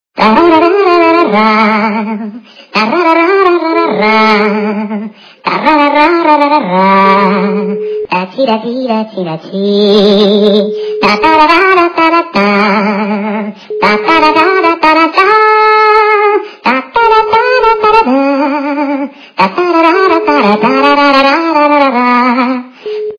» Звуки » для SMS » Голос - Та ра ра
При прослушивании Голос - Та ра ра качество понижено и присутствуют гудки.
Звук Голос - Та ра ра